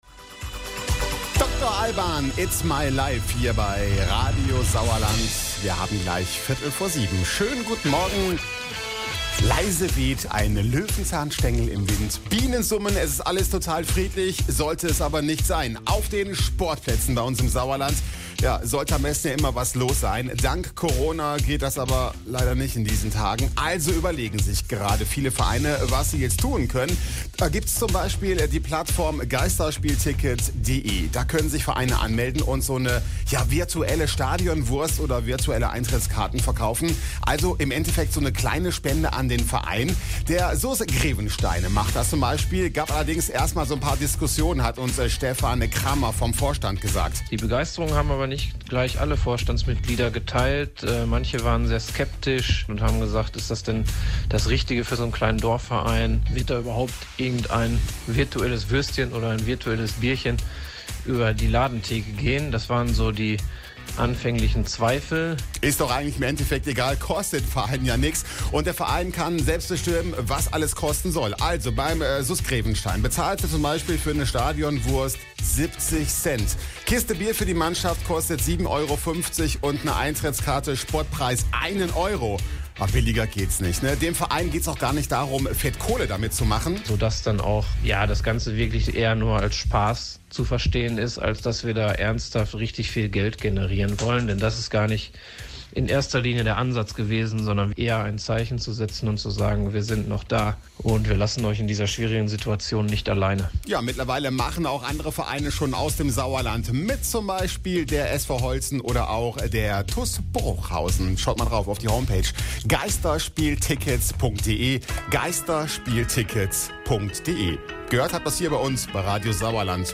Geisterspieltickets im Gespräch bei Radio Sauerland - SuS 1921 Grevenstein e.V.